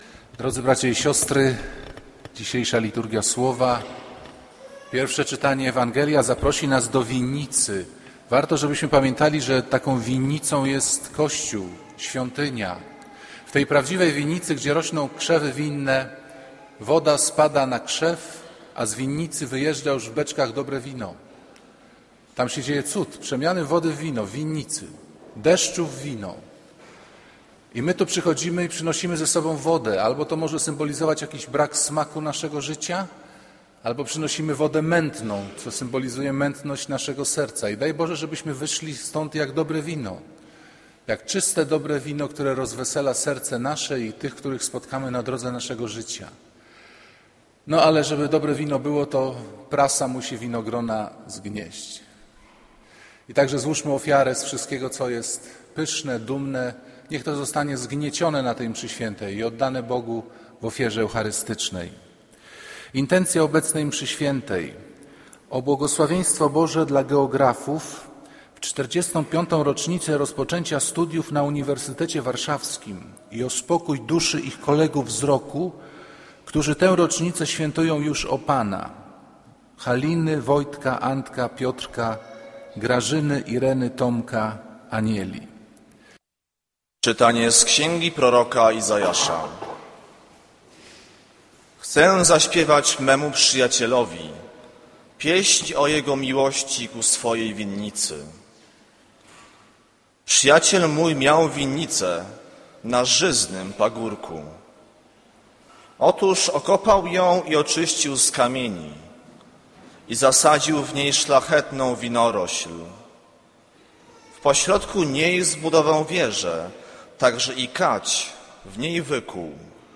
Kazanie z 2 października 2011r.